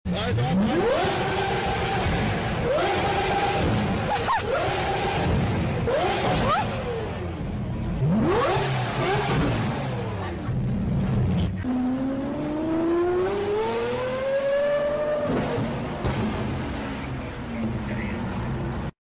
Which exhaust ? 🏎 If you love engine sounds then you are on right place. Enjoy videos of V6, V8, V12, rotary engine and many more tuned vehicles.